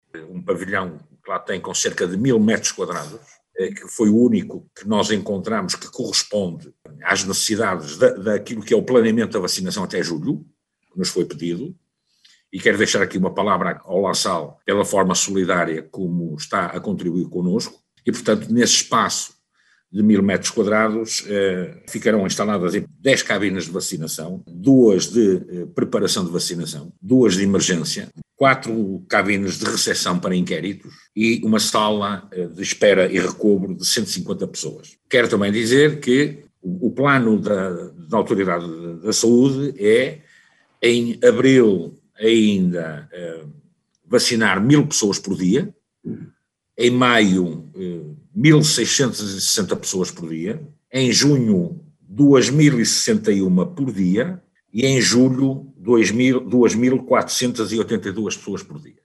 O local encontrado foi o Pavilhão Desportivo do Colégio La Salle, em Barcelinhos. O autarca barcelense, Miguel Costa Gomes faz a descrição do local: